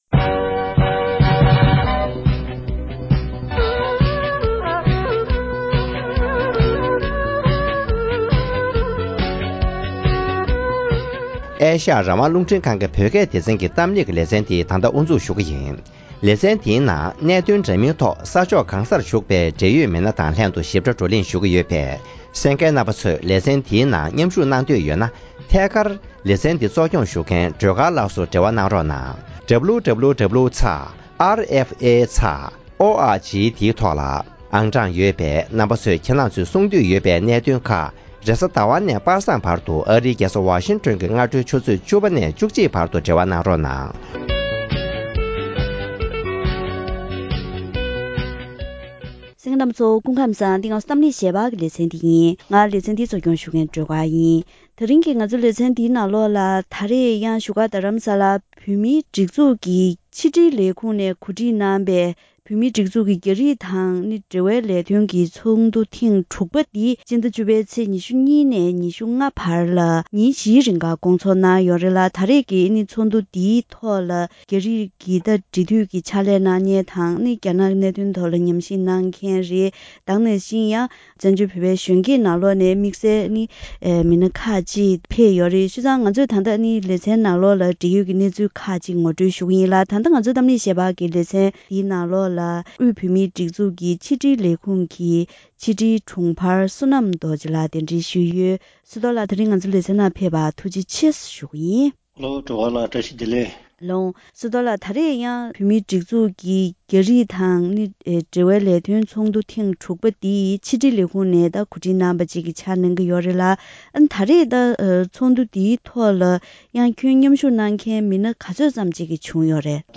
འབྲེལ་ཡོད་དང་བཀའ་མོལ་ཞུས་པ་ཞིག་གསན་རོགས་གནང་།